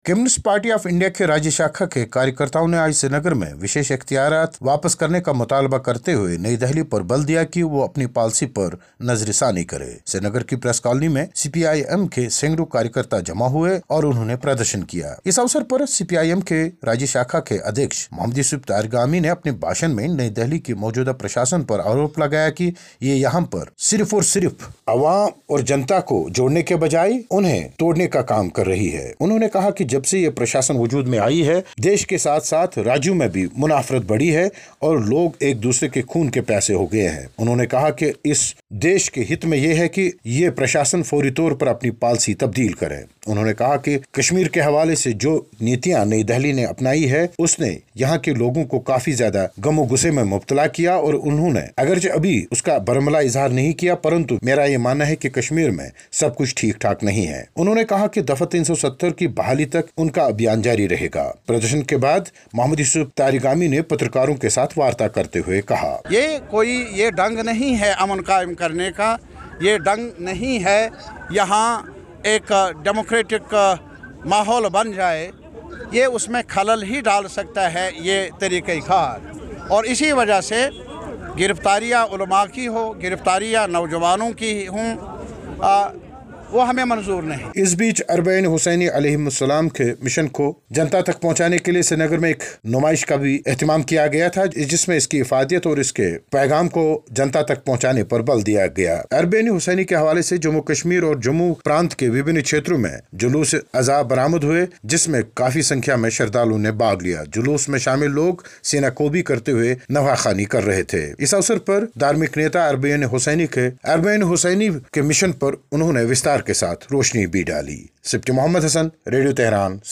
केन्द्र सरकार के विरुद्ध जमा हो रहे कश्मीरी दल... रिपोर्ट